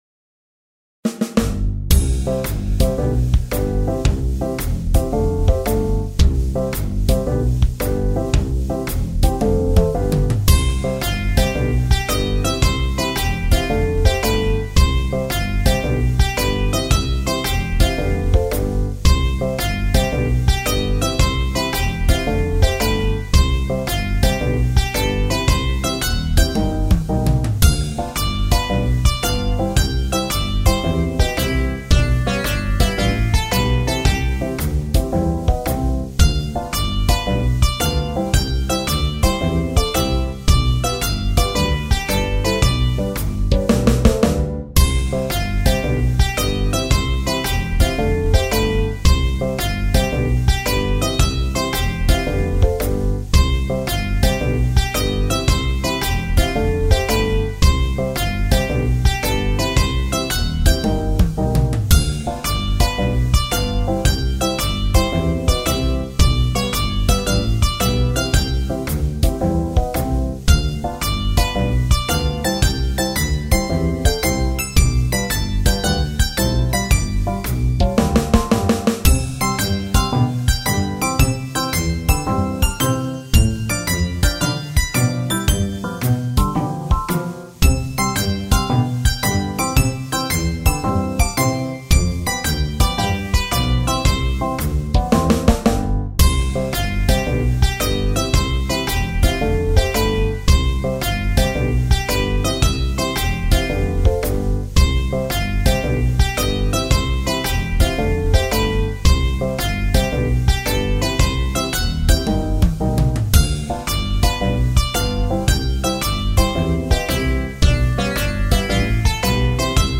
ジャズロング民族